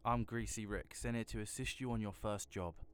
Voice Lines
Im greasy rick, sent here to assist you on your first job.wav